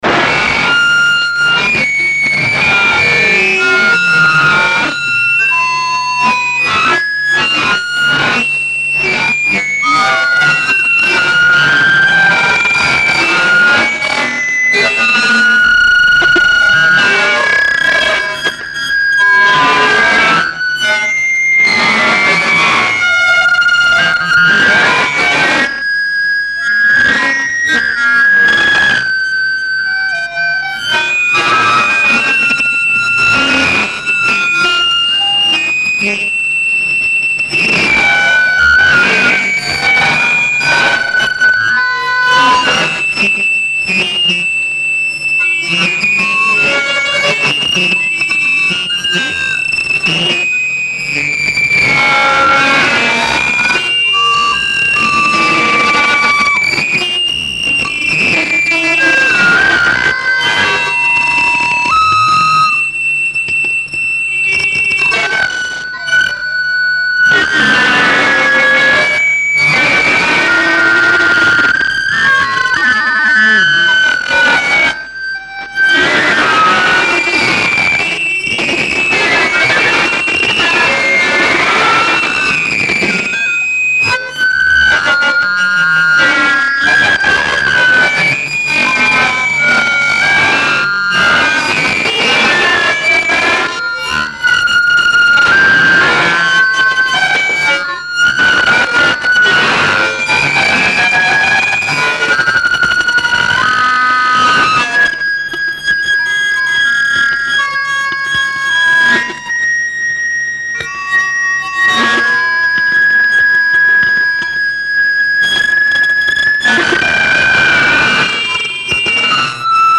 For fans of extreme sounds !